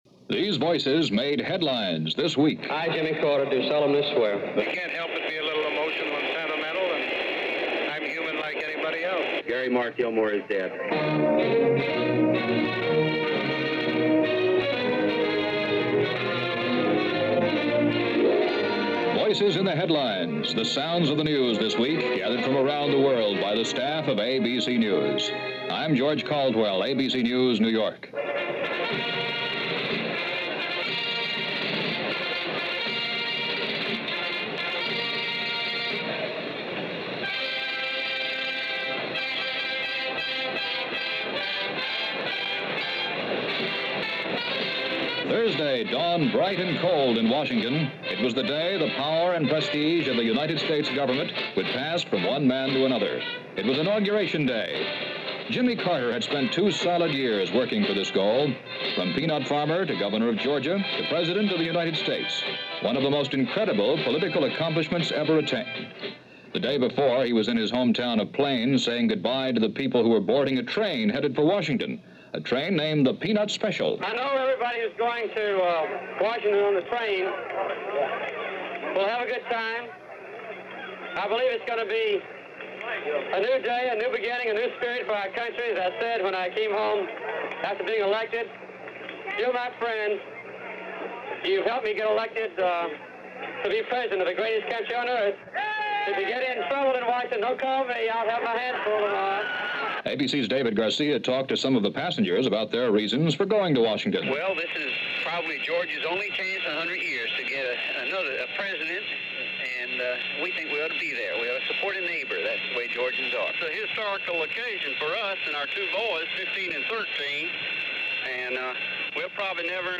Voices In The Headlines – ABC Radio News